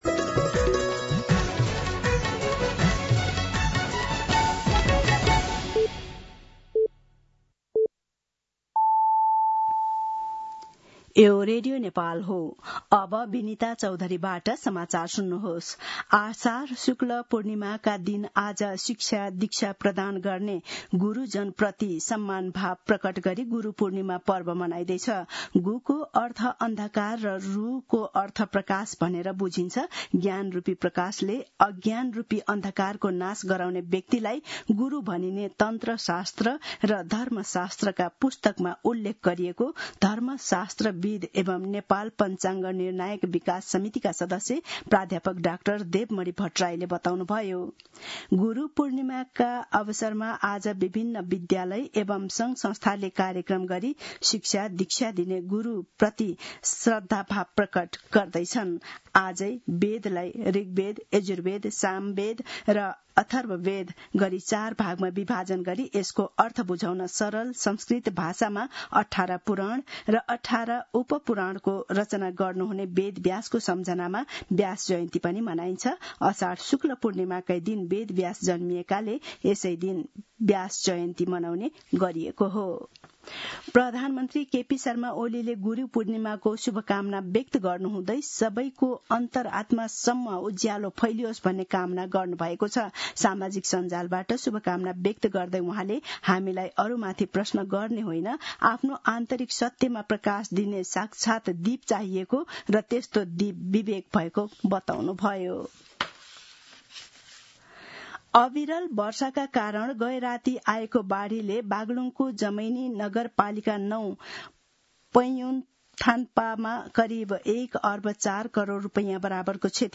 मध्यान्ह १२ बजेको नेपाली समाचार : २६ असार , २०८२